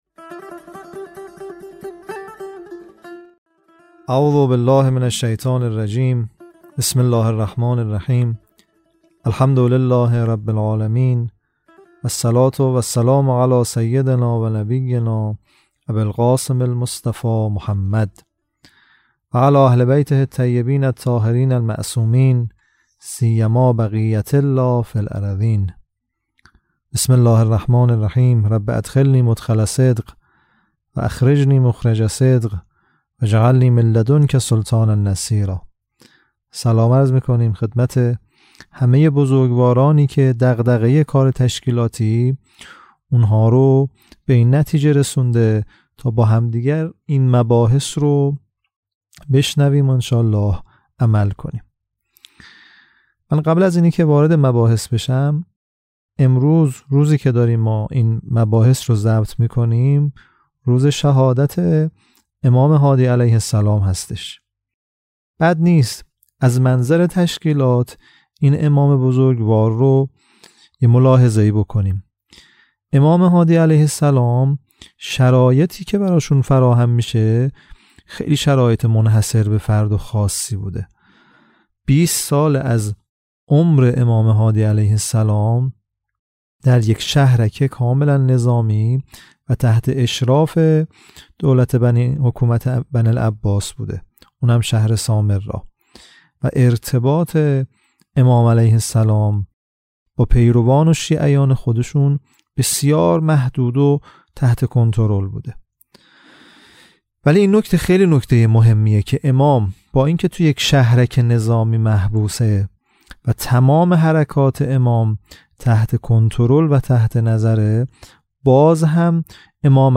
سی و دومین جلسه «دوره آموزشی تشکیلات»